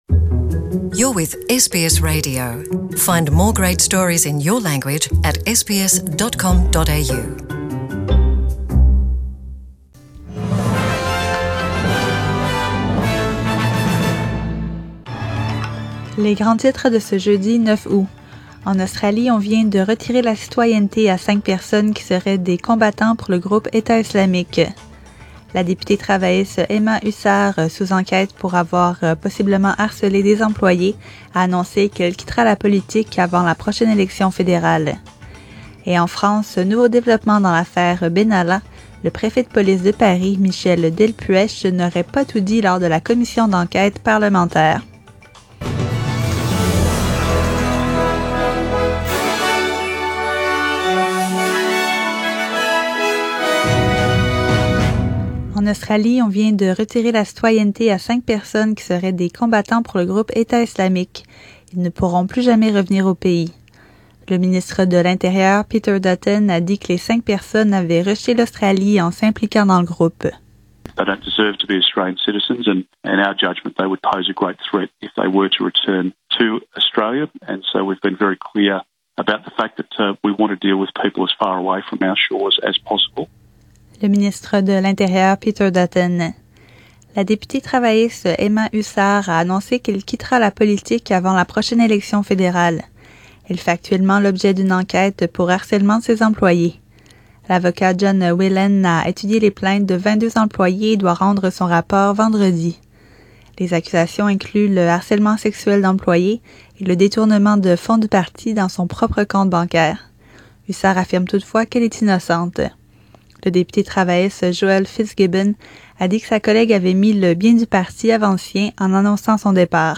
SBS French : Journal du 9 août